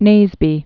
(nāzbē)